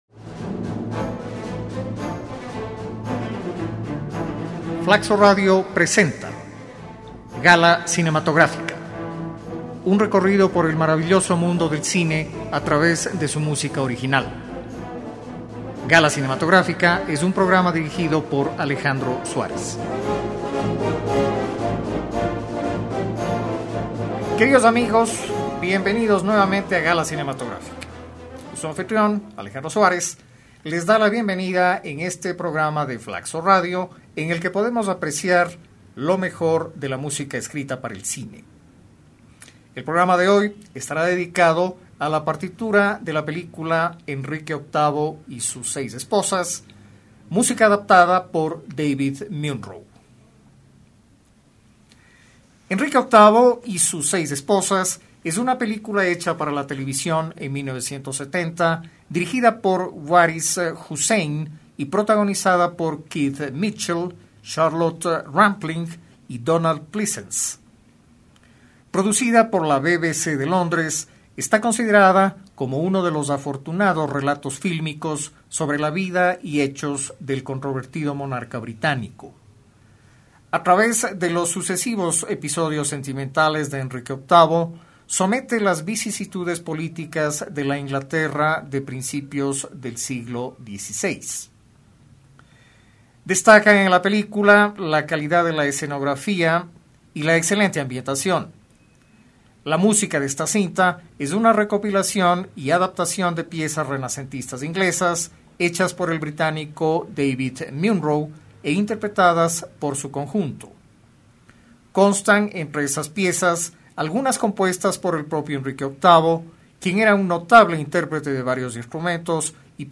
recopilación y adaptación de piezas renacentistas inglesas